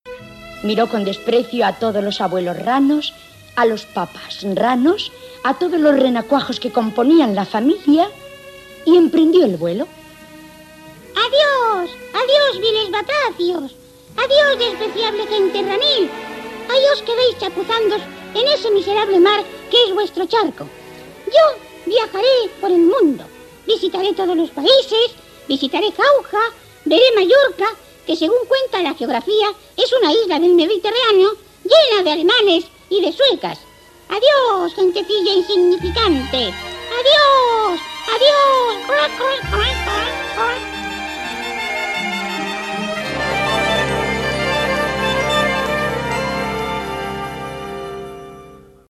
Fragment d'una ficció infantil
Ficció